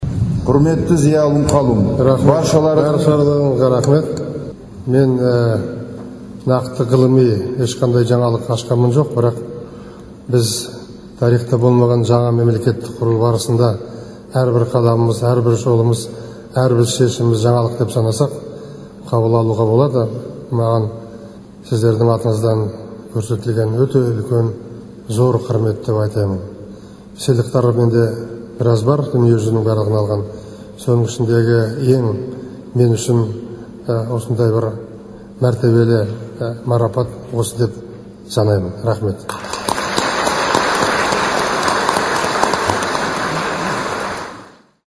Қазақстан президенті Нұрсұлтан Назарбаевтың өзіне «Ғасыр ғұламасы» атағын берген Қазақстан ғалымдарымен кездесуде айтқаны.